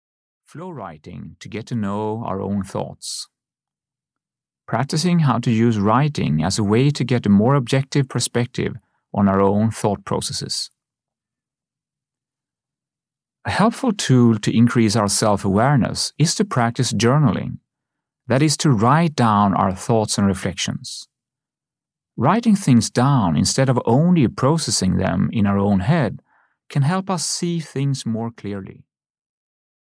Audio knihaFlow Writing To Get To Know Our Thoughts (EN)
Ukázka z knihy